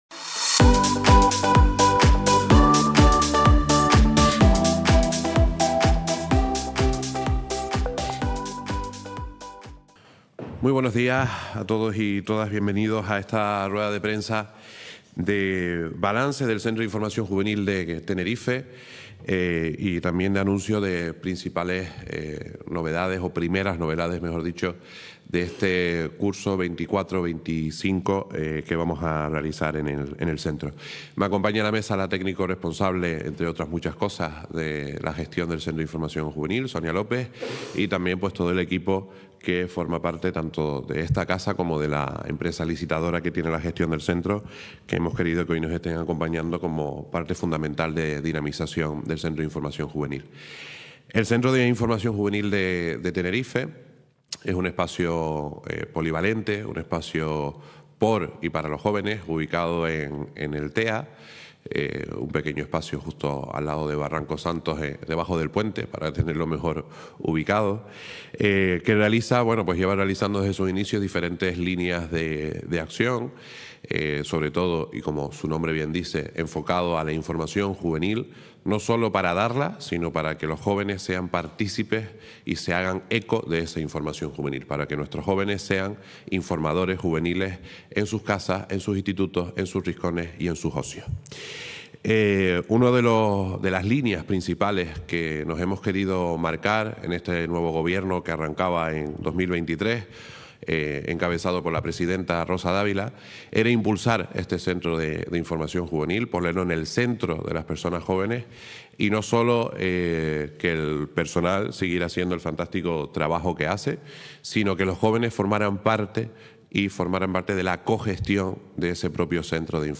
El Cabildo de Tenerife, a través del área de Juventud y Formación, ha presentado hoy, en rueda de prensa, el programa de nuevas actividades del Centro de Información Juvenil (CIJ), ubicado en el TEA (Tenerife Espacio de las Artes) entre las que...